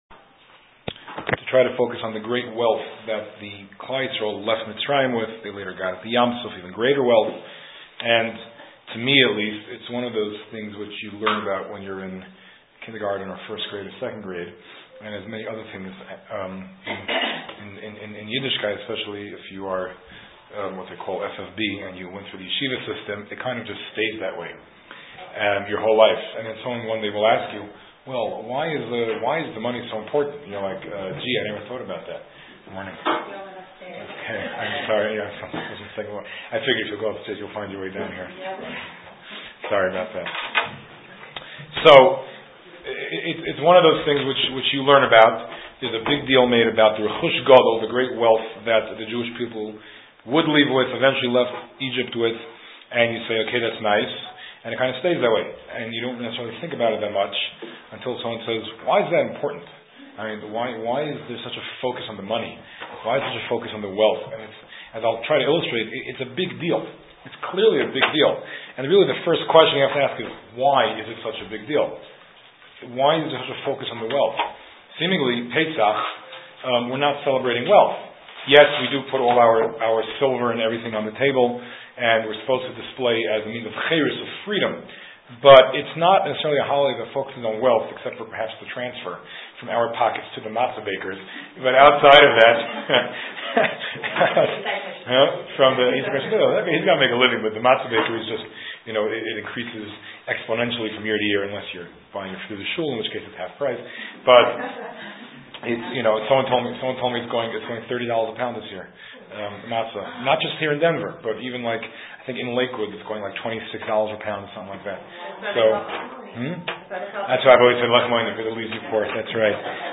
The class was held in two locations: at the Kollel’s West Side location and in Greenwood Village at Aish Denver.